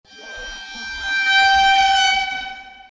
Download Train Horn Fades sound effect for free.
Train Horn Fades